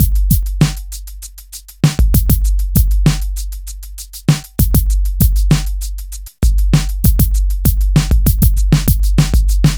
Rock Star - Beat 02.wav